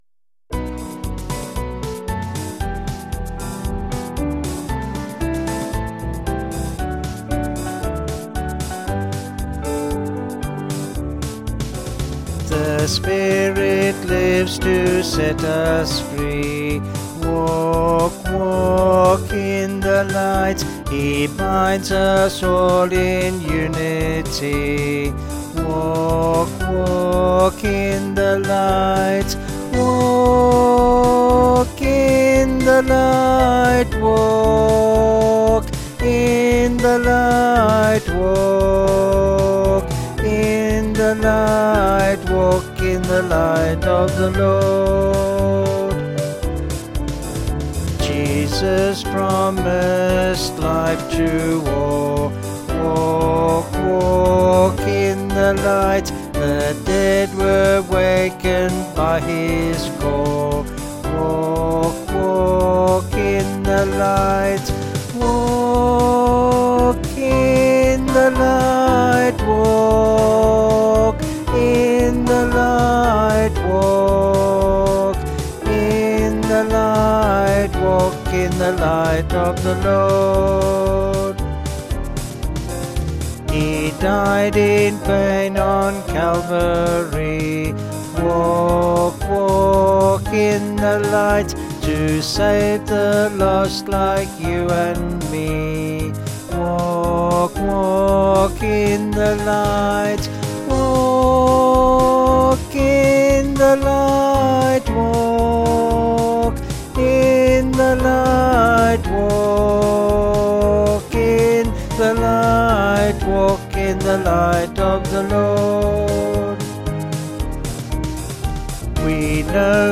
(BH)   6/G-Ab-A
Vocals and Band   264.6kb